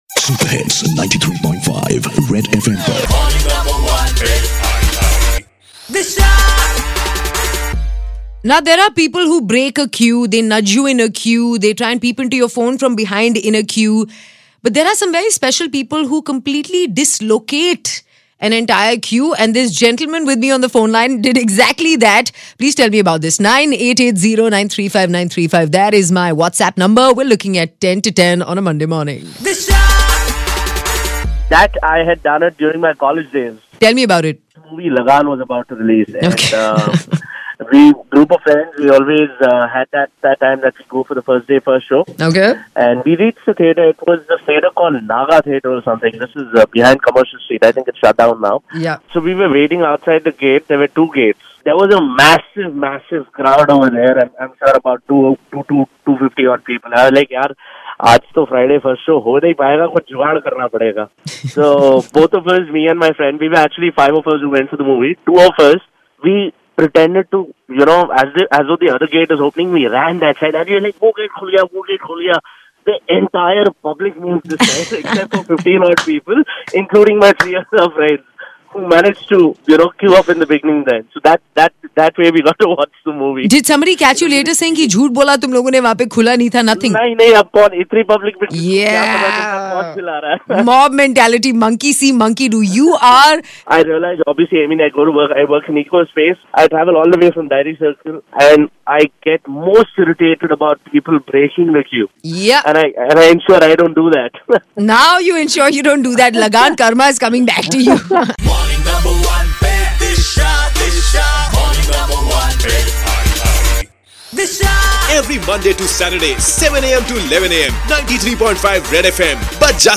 A caller narrating his queue cutting saga